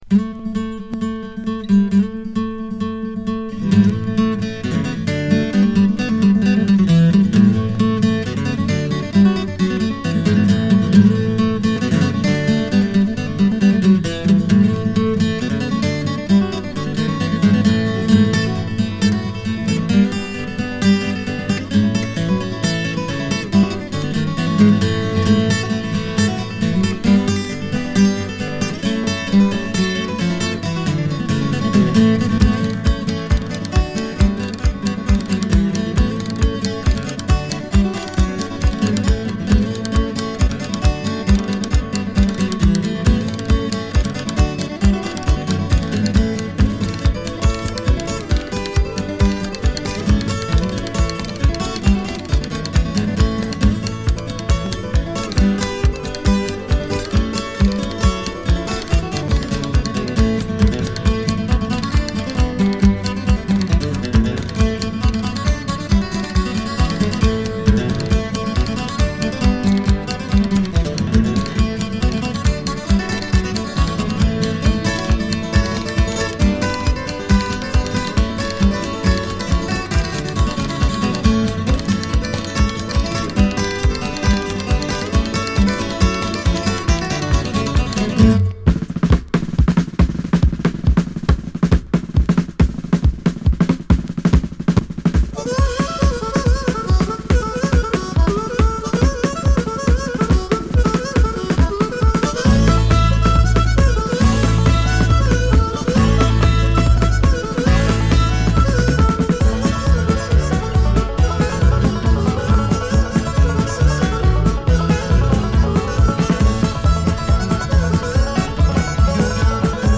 Quelques morceaux ou extraits enregistrés sur scène :